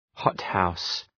Προφορά
{‘hɒt,haʋs}